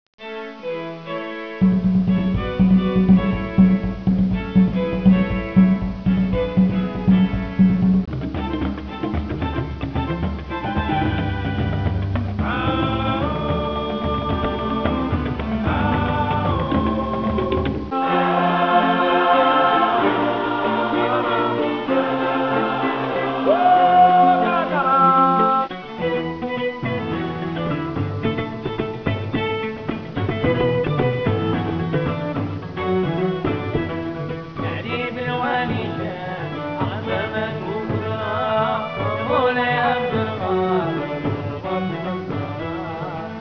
Modern Classical-Pop.
Sample 4 songs (472 Kbytes) 8 bit, 11KHz